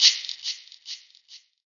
MZ Shaker [Plugg Shaker #2].wav